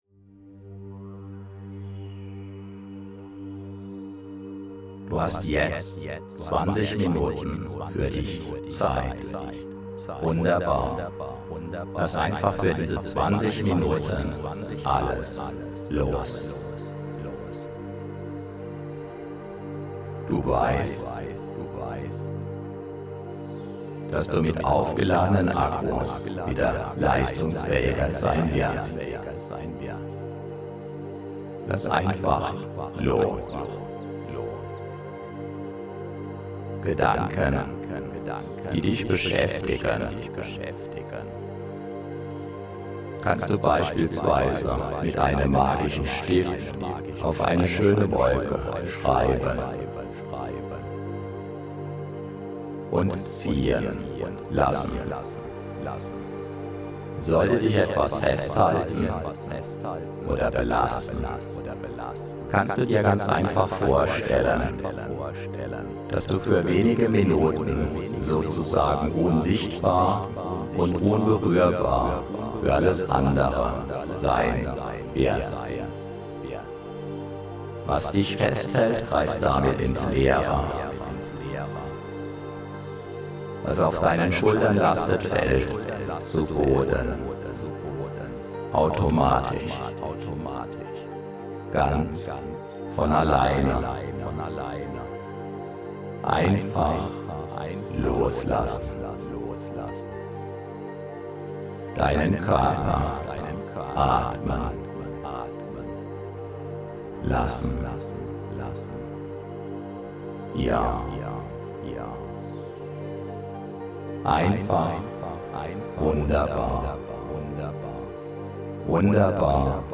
10-Minuten-Hörproben der 20-Minuten-Powernap in allen 16 Varianten
Variante 20 min, doppelt-asynchrone Doppelinduktion, Hintergrundmusik #1
So sind z. B. die Sprechpausen bei einer 30-Minuten-Variante deutlich länger als bei einer 9-Minuten-Variante etc.